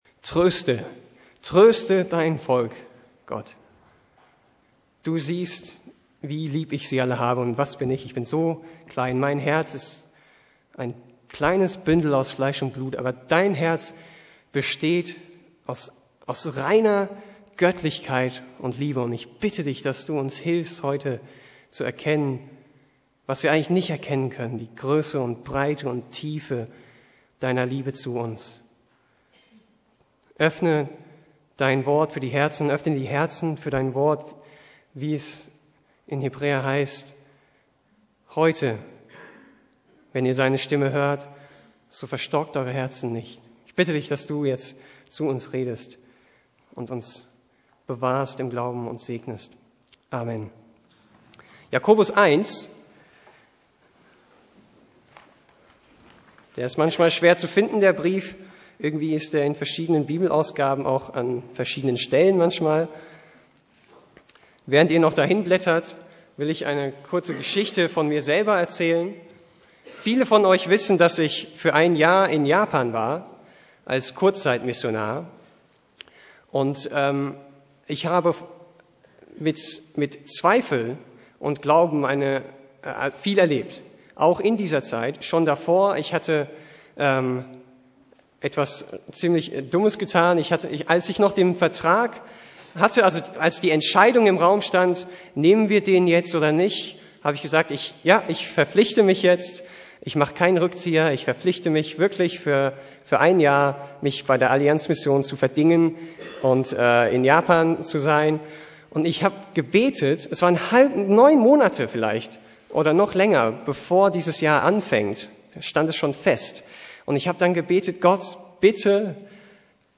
Predigt - Jakobus 1,1-18 "Glauben und Zweifeln"